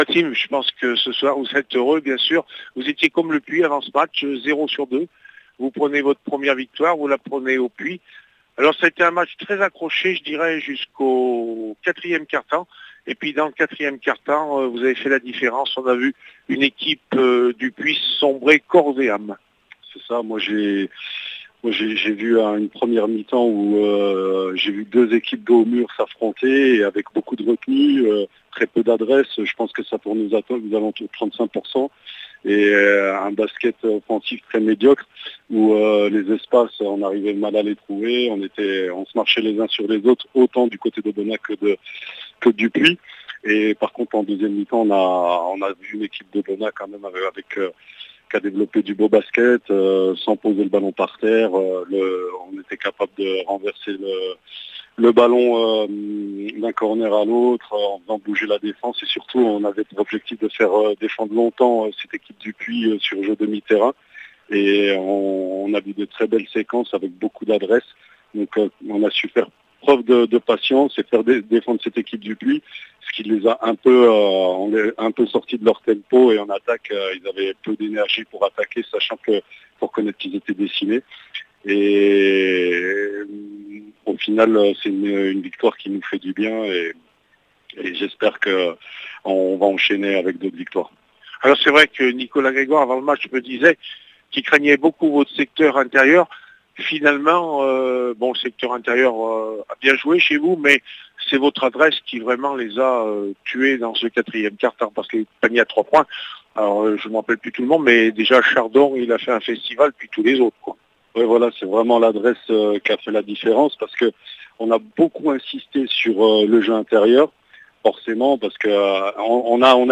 2 octobre 2016   1 - Sport, 1 - Vos interviews, 2 - Infos en Bref   No comments
BASKET NATIONALE 2 ASMB LE PUY 66-78 AUBENAS REACTION APRES MATCH 01/10/16